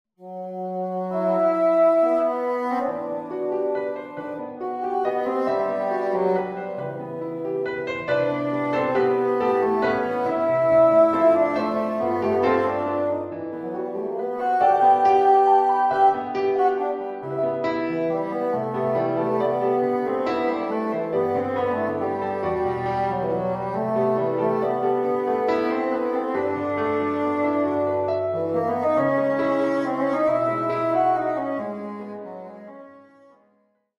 Sumptuous jazz style.